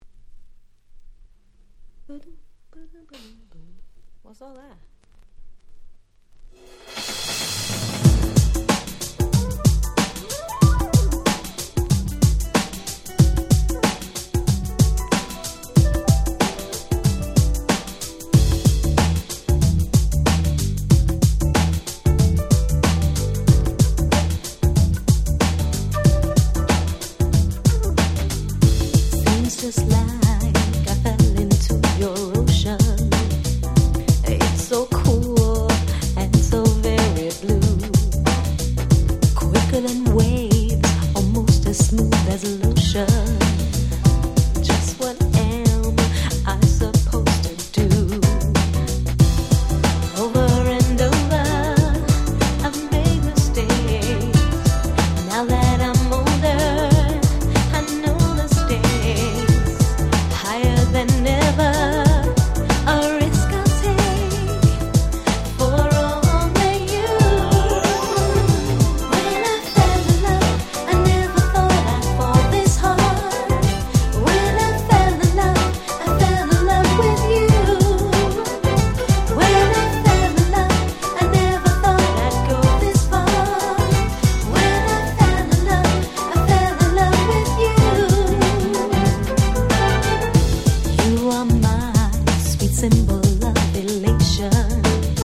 94' Very Nice R&B !!